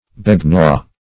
Search Result for " begnaw" : The Collaborative International Dictionary of English v.0.48: Begnaw \Be*gnaw"\, v. t. [p. p. Begnawed , (R.)